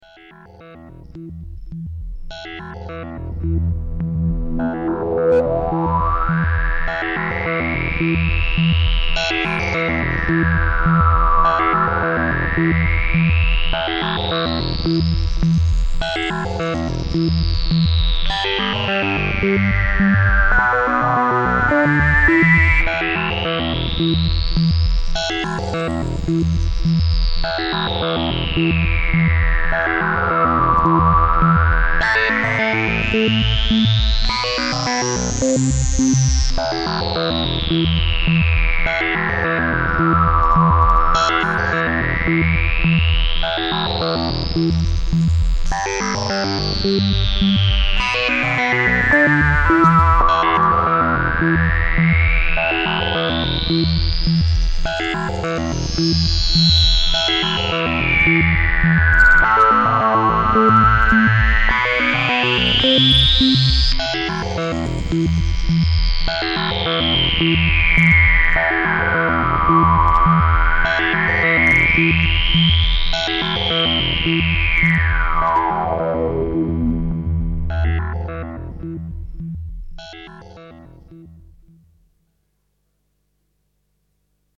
next clips featuring just the suboscillators - their waveforms are more conventional than the main vcdo output, and lend themselves to some subtractive synthesis.
feature 2 x waverider subs through different vcfs, with an lfo modulating the waveform/octave of one waverider to create a pseudo-sequence - no sequencers or arpeggiators were used.